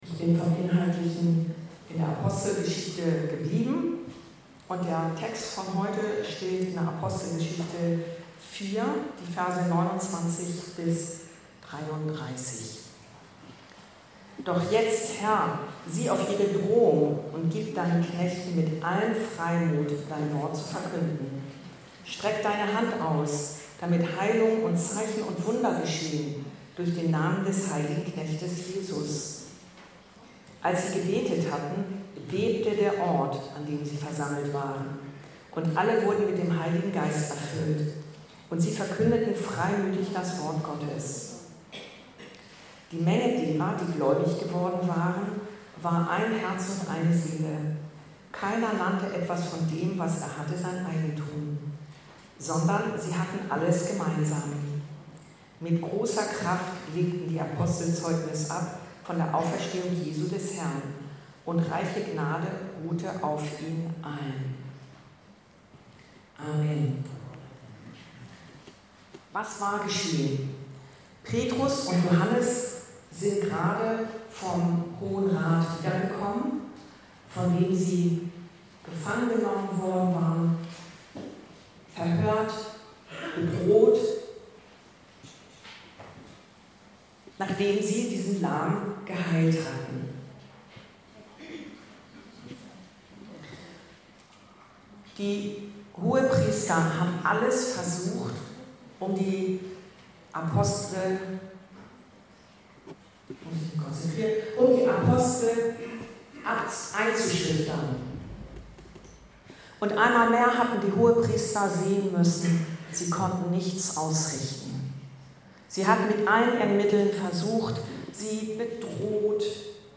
Erwarte mehr von Gott – Vertraue dem Heiligen Geist ~ Anskar-Kirche Hamburg- Predigten Podcast